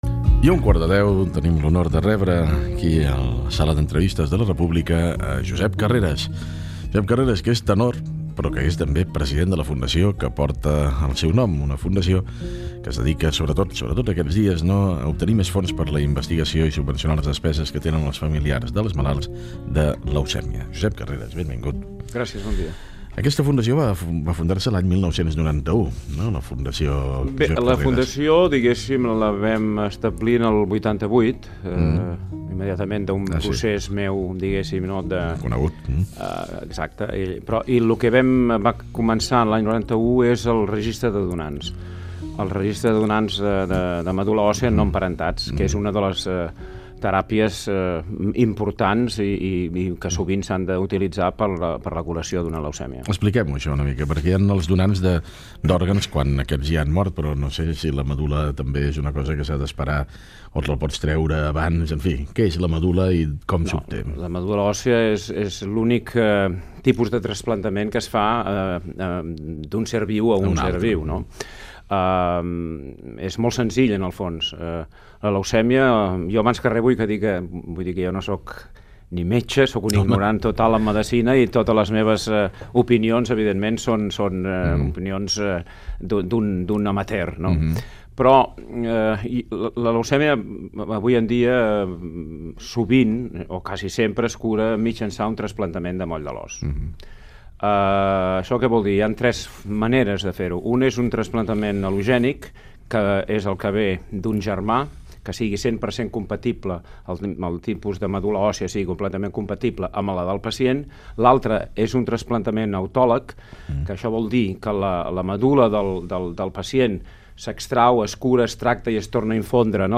Fragment d'una entrevista al tenor Josep Carreras sobre la seva Fundació de la leucèmia.
Info-entreteniment